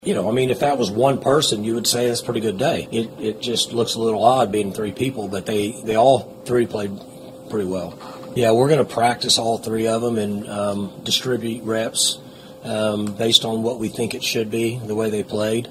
Oklahoma State head football coach Mike Gundy still had his regular press conference on Monday afternoon despite the holiday.